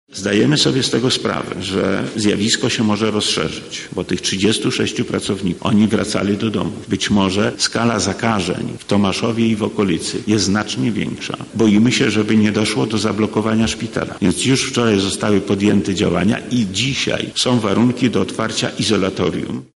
Wczoraj o 18.00 odbyło się posiedzenie zarządzania kryzysowego na poziomie starosty tomaszowskiego– mówi Wojewoda Lubelski Lech Sprawka: